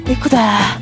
Worms speechbanks
Fire.wav